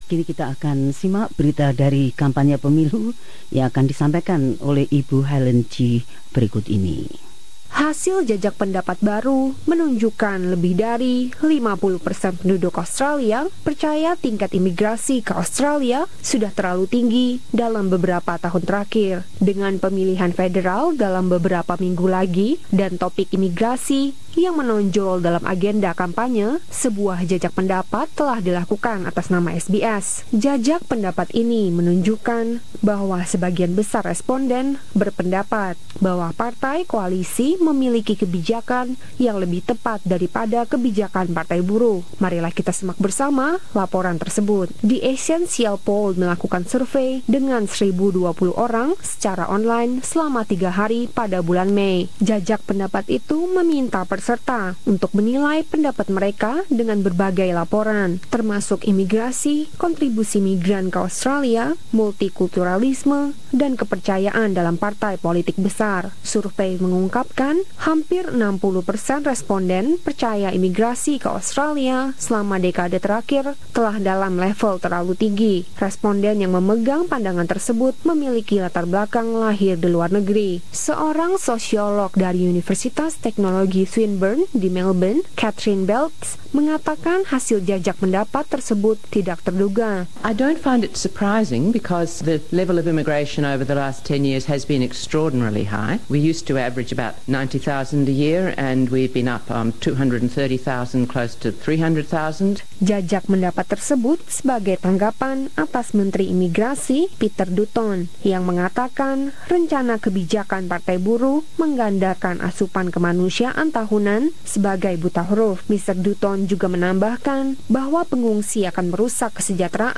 Laporan ini menyoroti hasil dari jajak pendapat terakhir Essential Poll tentang sikap warga Australia terhadap imigrasi.